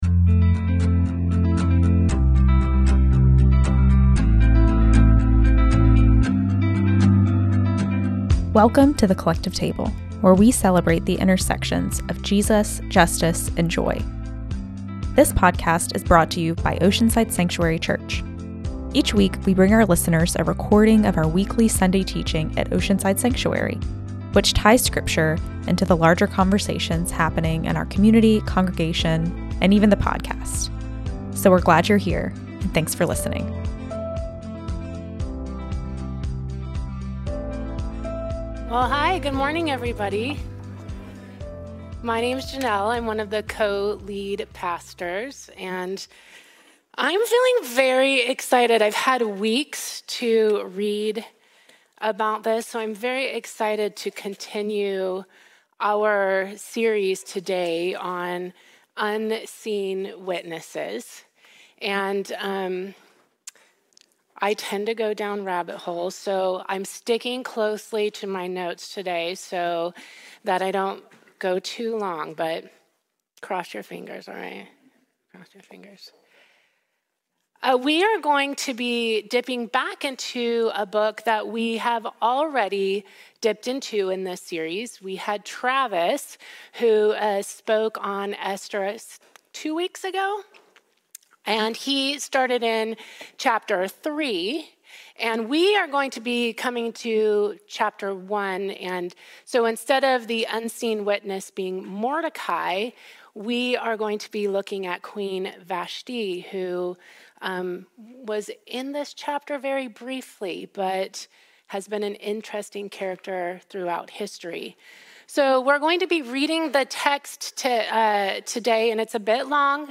A collection of teachings from our Sunday gathering and classes